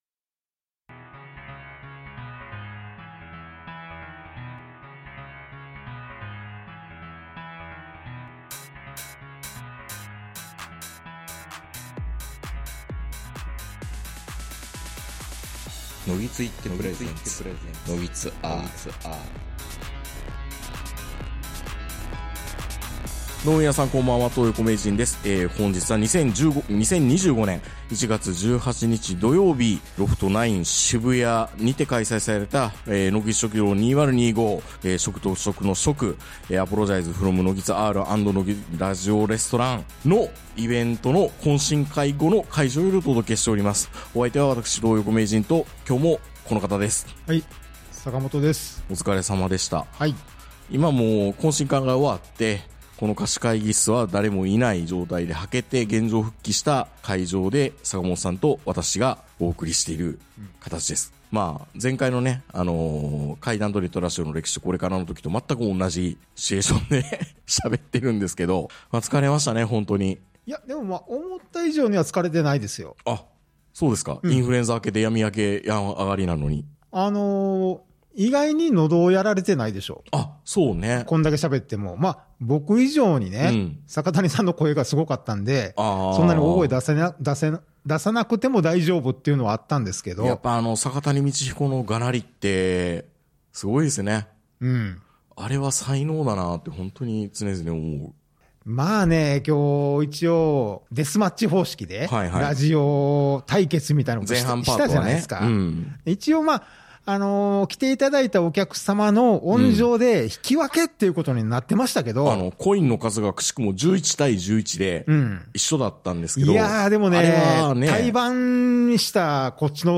・イベント・懇親会終了後の貸し会議室よりお届け・ラジオデスマッチ・ラジオ食堂に完敗 ・人生の先輩の方々の出席！